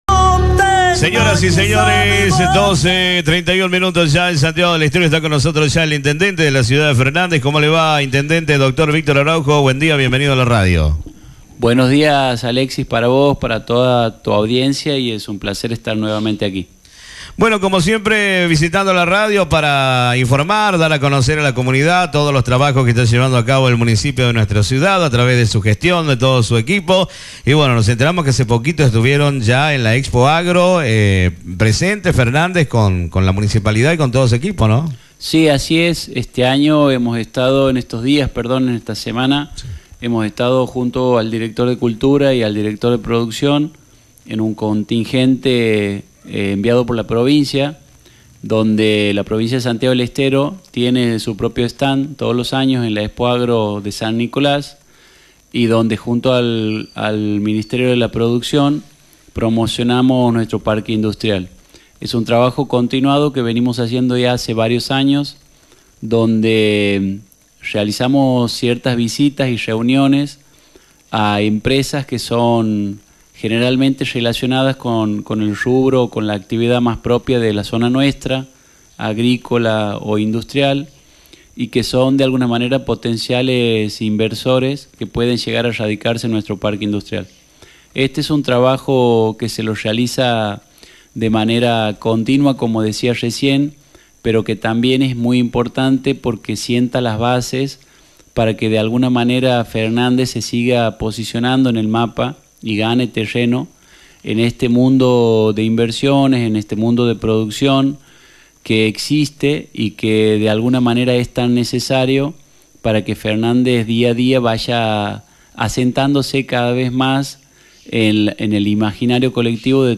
Escuchá la entrevista del sábado 15/03/2025: